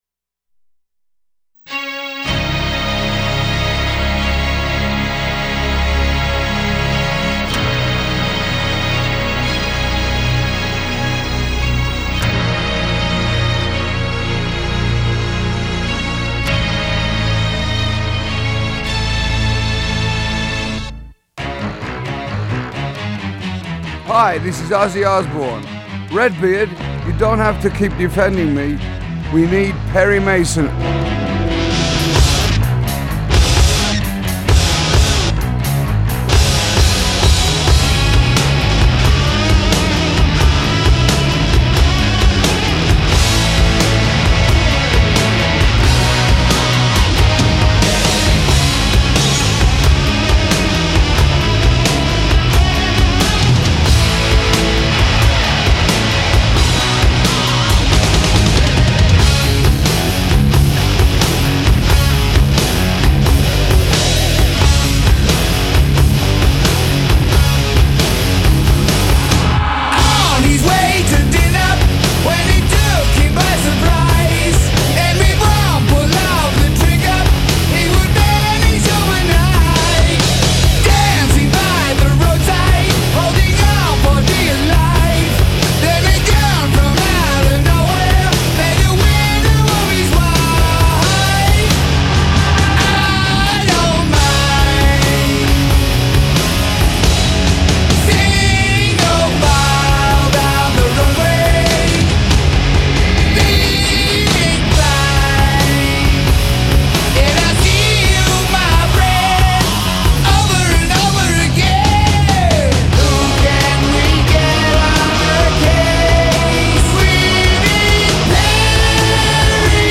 One of the world's largest classic rock interview archives